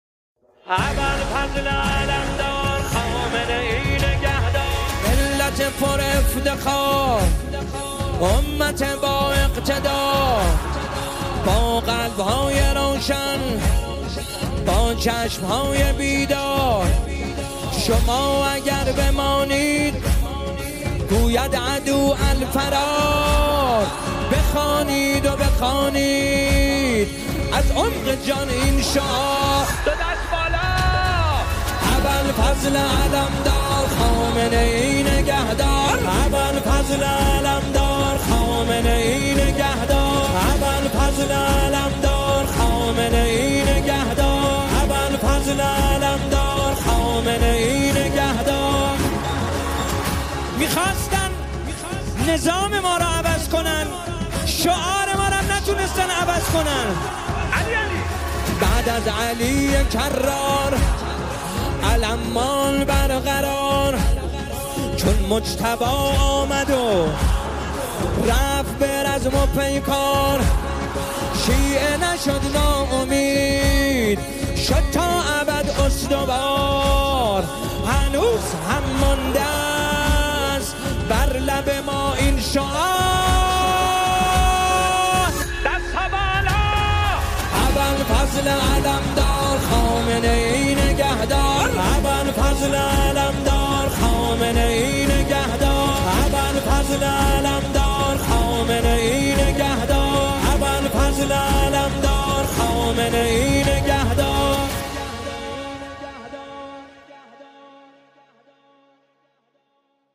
مداحی حماسی